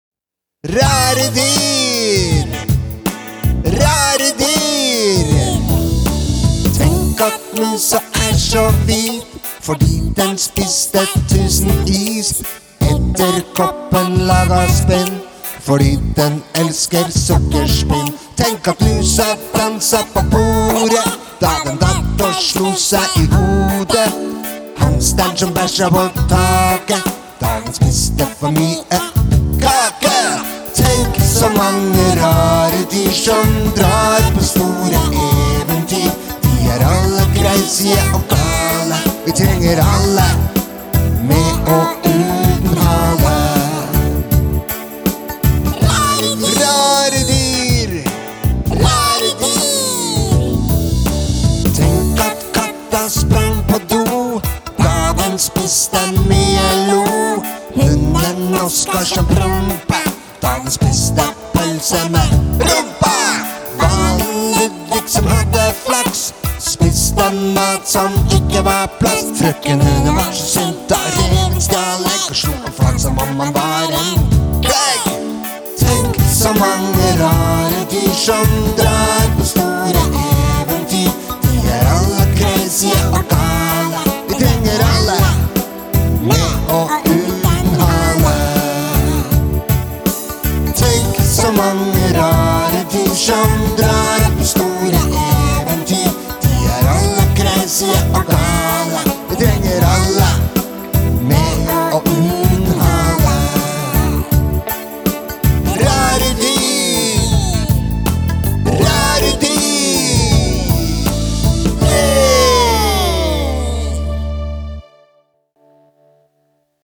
Mandag 6. juni 2022: RARE DYR – BARNESANG (Sang nr 276 på 276 dager – 89 sanger igjen…)